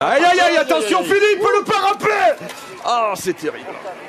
Category: Sports Soundboard